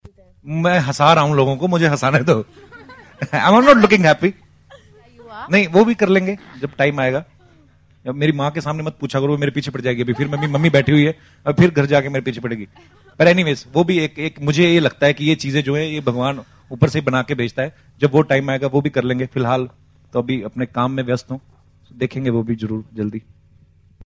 उनकी फिल्म 'किस किसको प्यार करूं' का हाल ही में ट्रेलर लांच हुआ. इस मौके पर कपिल से हुआ शादी का ज़िक्र। सुनिए क्या बोले कपिल शादी के बारे में.